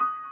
piano39.ogg